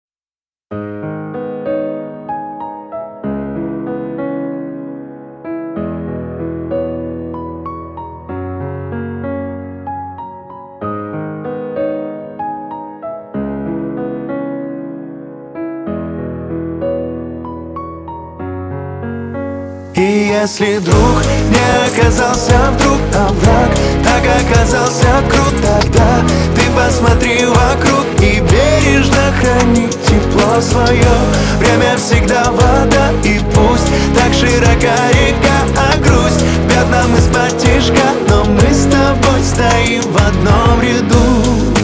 Русские Жанр: Поп Просмотров